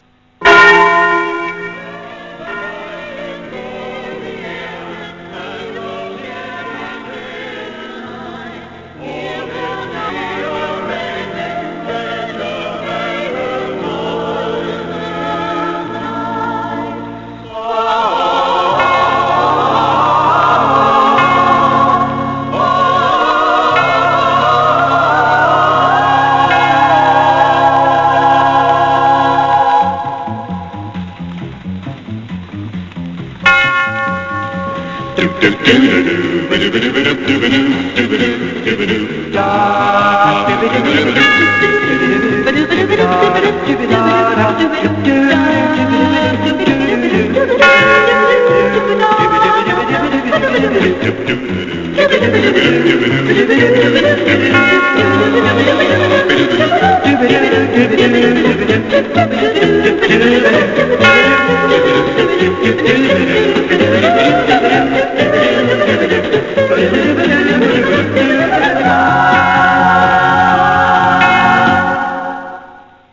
intro theme